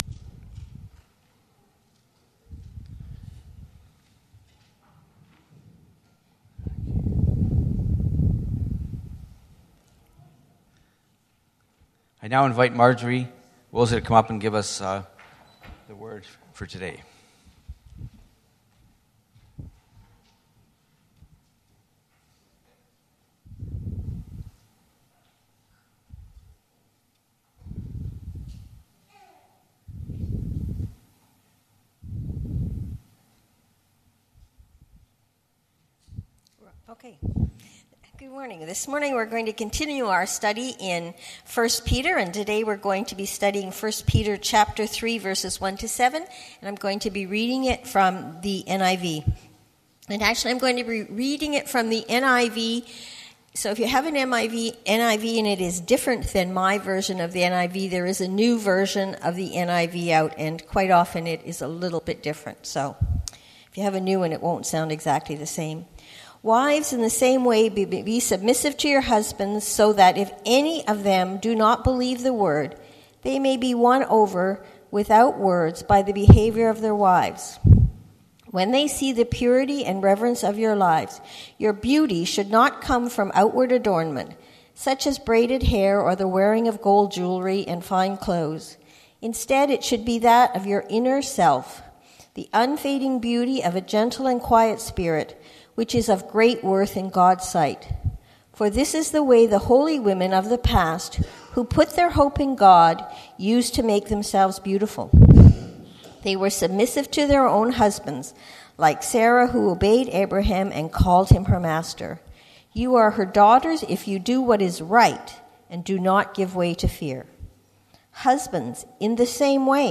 This sermon is based on 1 Pet 3:1-7.